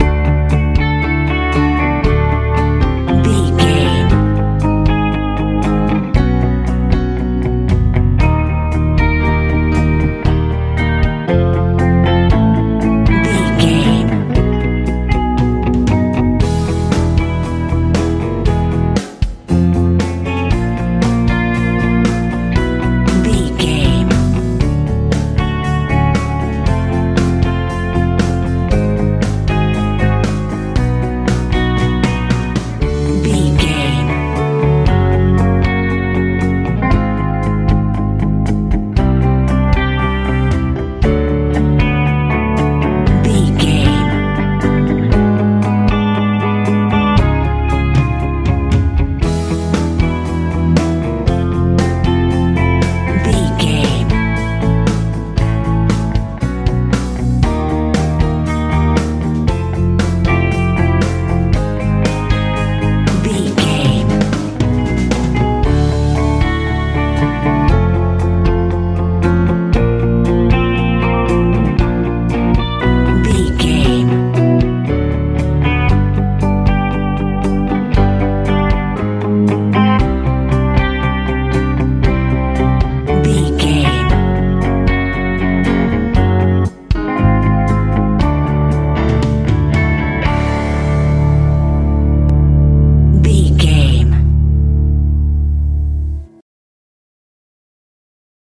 lite pop feel
Ionian/Major
D
bright
cool
piano
electric guitar
bass guitar
drums